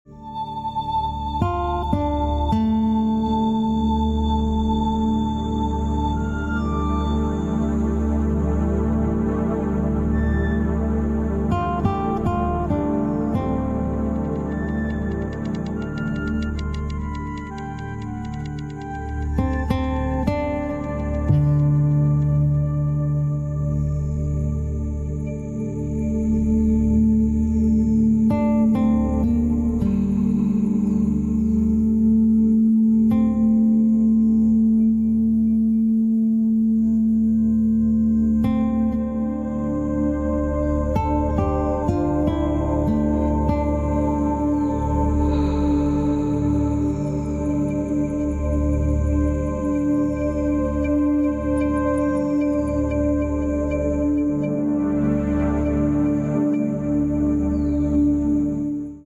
400 hz | Meditative Harmony: Peace & Security.